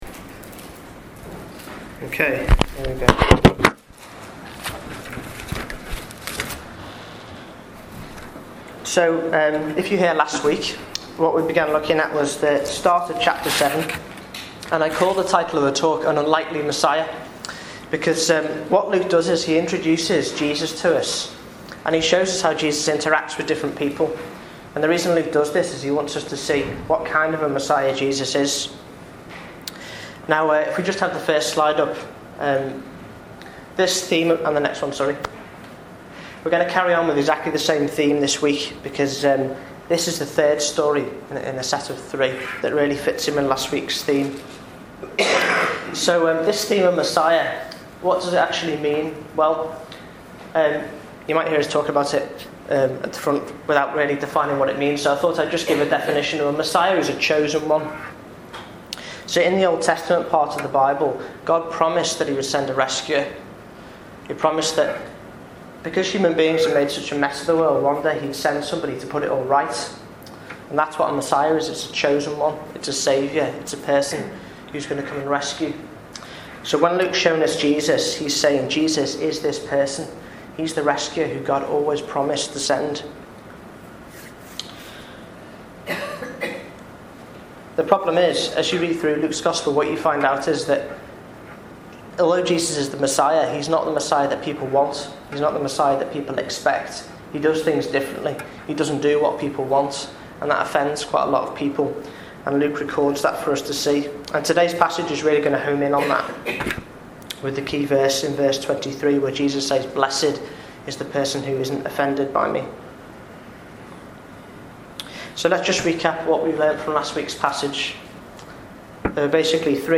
talk on Luke 17:18-35 on 24th November as part of our Luke series.